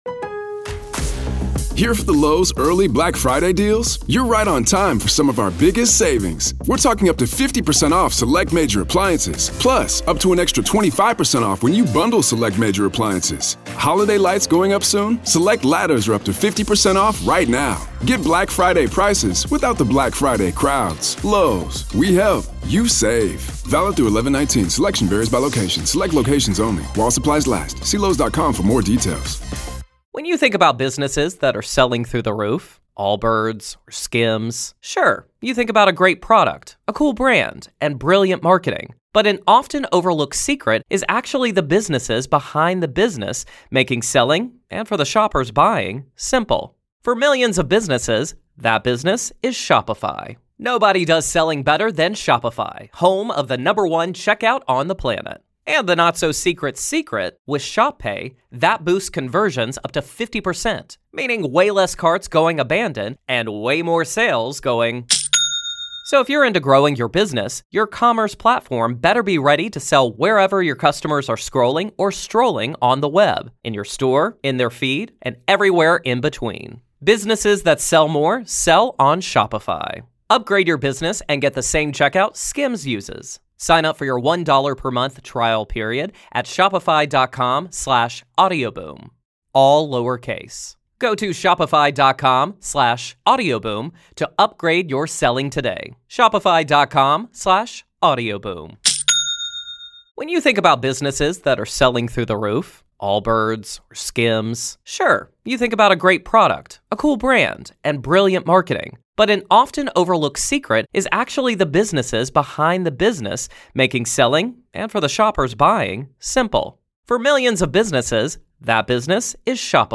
This discussion shows how enmeshment can feel like a cult on a micro level: unquestioned authority, alternate reality, roles assigned to each family member, and loyalty above all else.